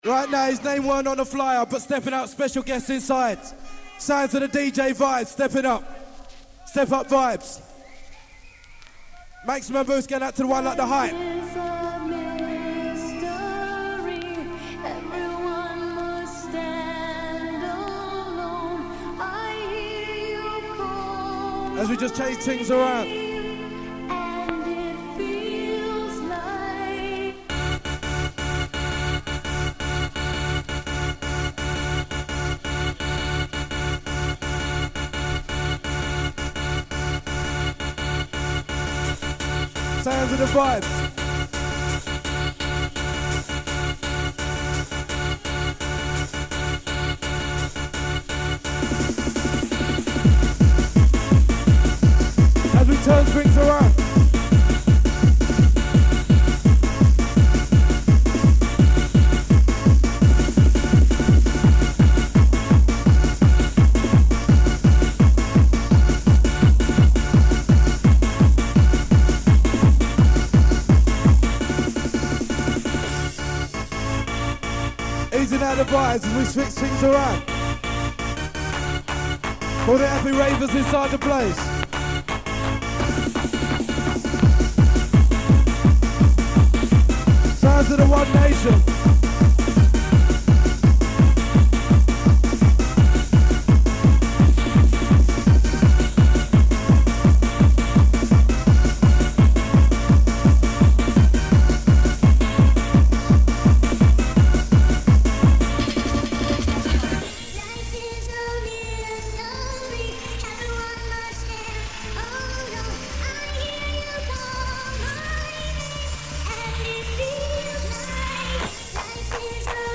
loads of live rave tape packs from the 90s in mp3 format
not really hardcore though